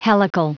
Prononciation du mot helical en anglais (fichier audio)
Prononciation du mot : helical